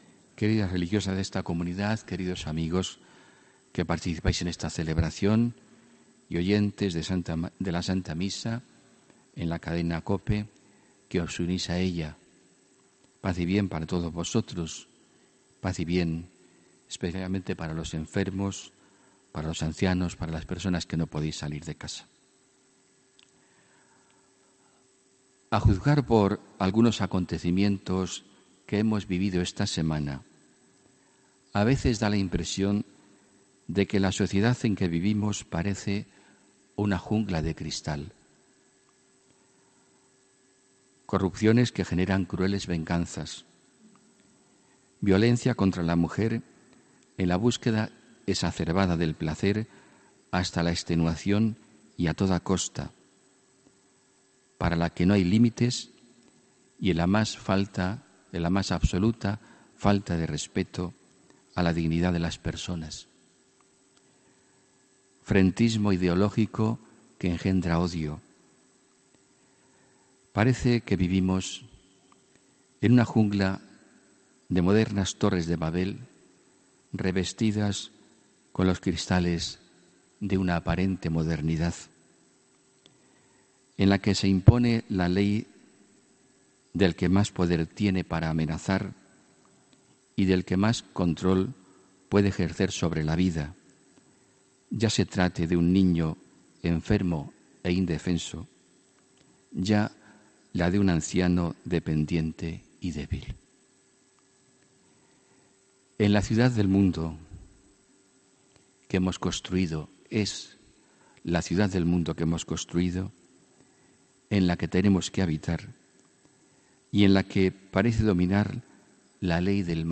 HOMILÍA 29 ABRIL 2018